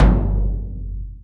kick.MP3